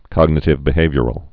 (kŏgnĭ-tĭv-bĭ-hāvyə-rəl)